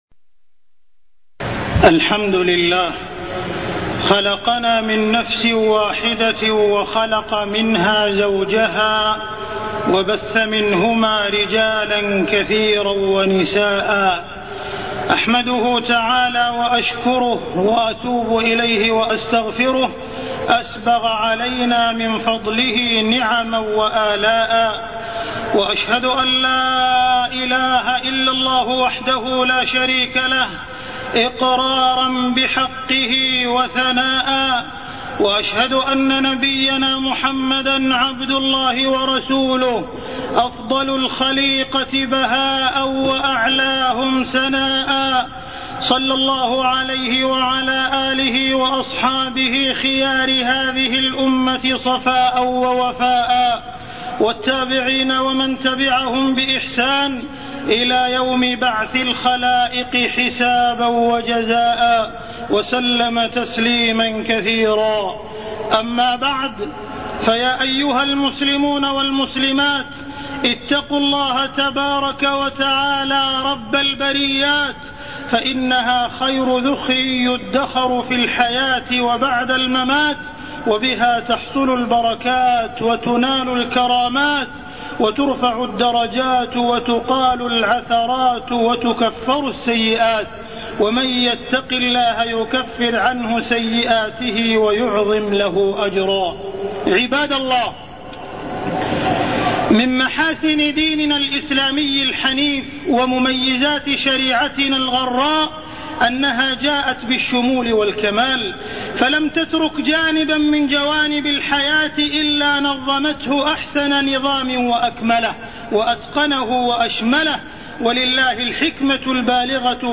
تاريخ النشر ١٢ ربيع الثاني ١٤٢١ هـ المكان: المسجد الحرام الشيخ: معالي الشيخ أ.د. عبدالرحمن بن عبدالعزيز السديس معالي الشيخ أ.د. عبدالرحمن بن عبدالعزيز السديس المرأة ومكانتها